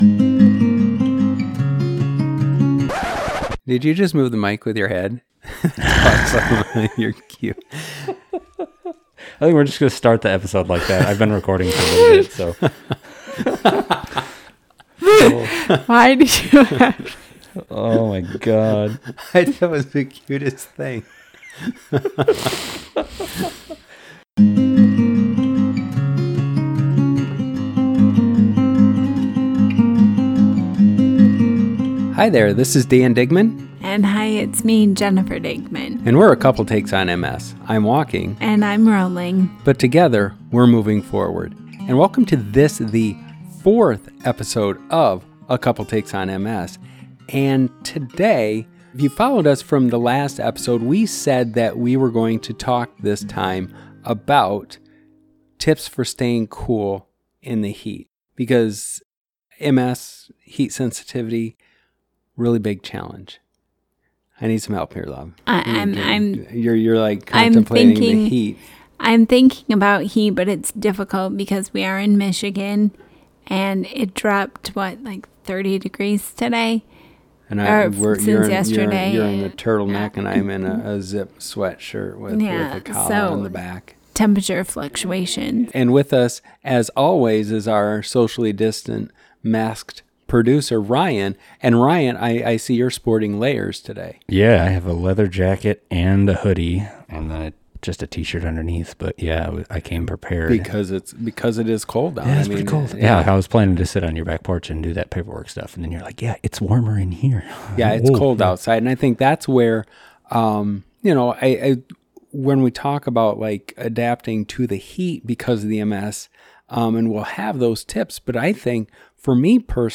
In a slight stray from standard procedure, the gang had some pizza and beer before recording. The results were a little looser, more casual, meandering conversation.